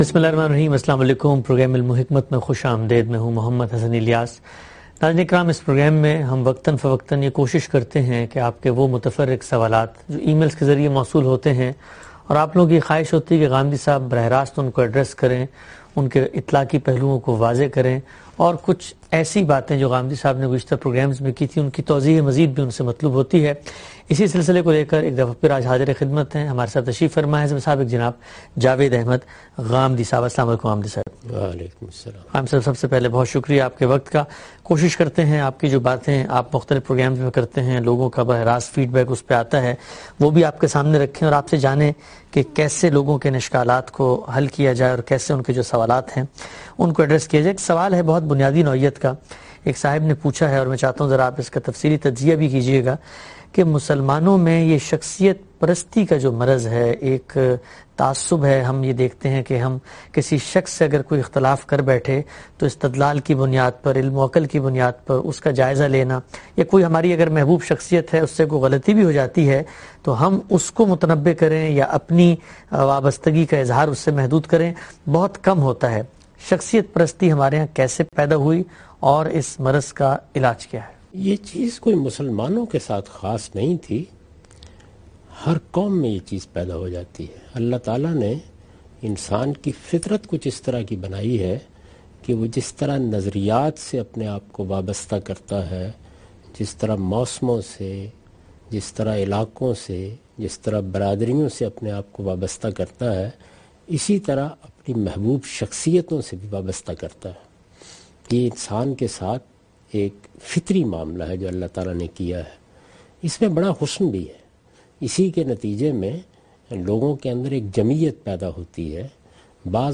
In this program Javed Ahmad Ghamidi answers the questions of different topics in program "Ilm-o-Hikmat".